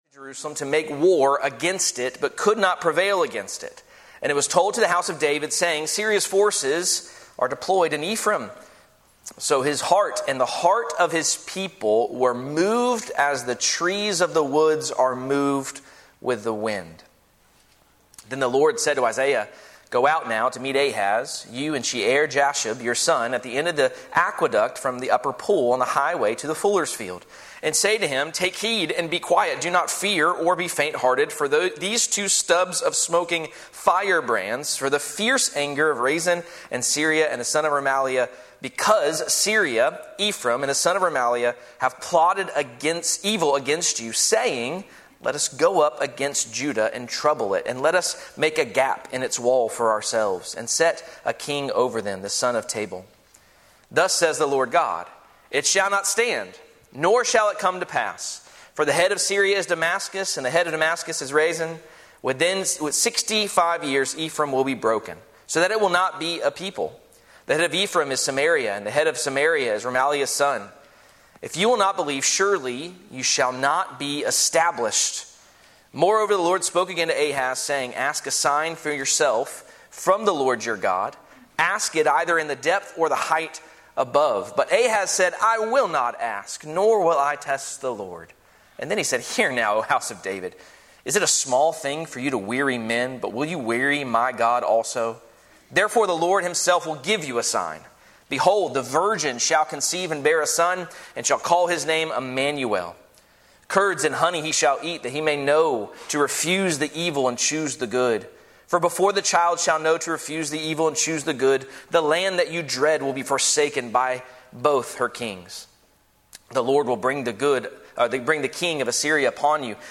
Current Message
Sermon